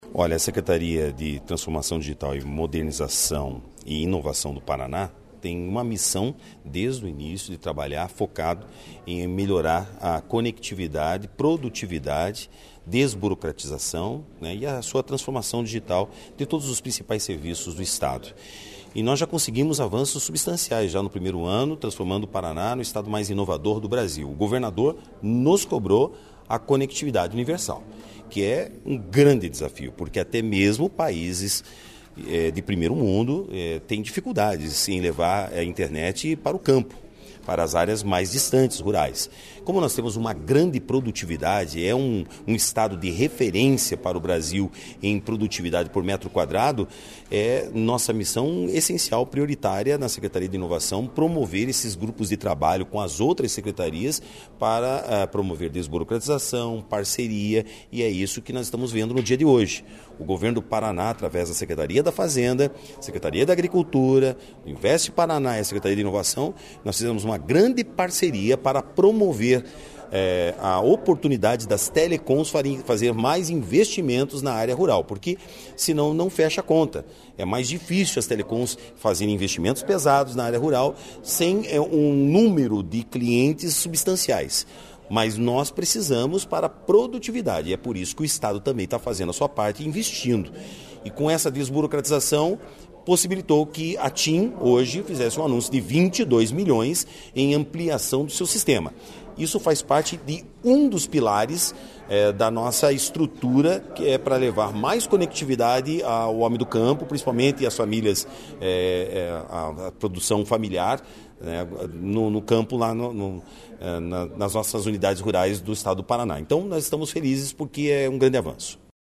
Sonora do secretário da Inovação, Marcelo Rangel, sobre o protocolo com a operadora TIM para ampliar a cobertura de internet e telefonia móvel nas áreas rurais do Paraná